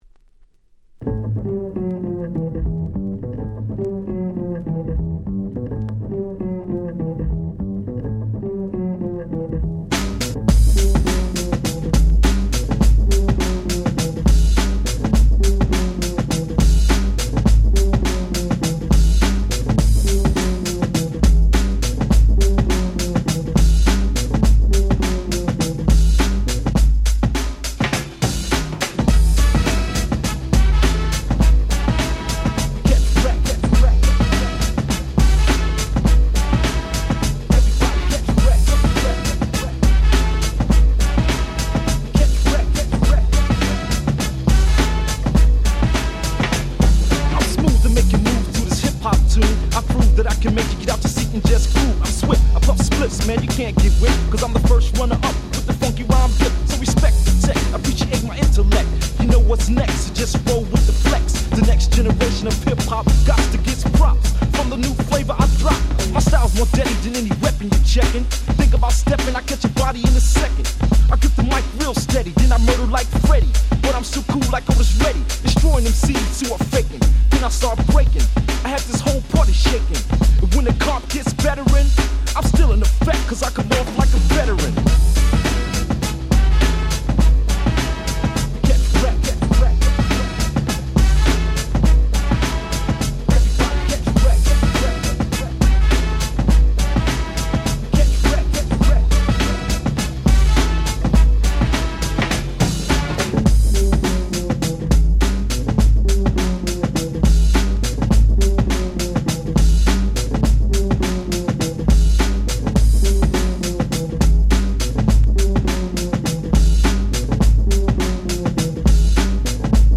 【Media】Vinyl 12'' Single (Promo)
96' Very Nice Break Beats !!
90's ブレイクビーツ R&B Hip Hop